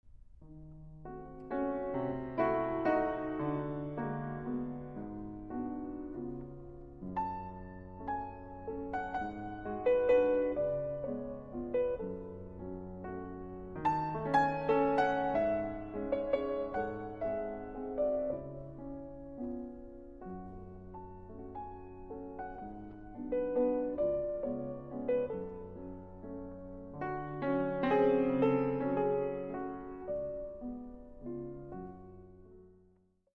〜ローズウッドの音をもう一度！〜
音響ではカーネギーホール、ムジークフェライン等と並んで世界のトップ10に選ばれた浜離宮朝日ホール、今回もまた「巨匠たちの伝説」のローズウッド、1887年製ピアノの音が鳴り響きます。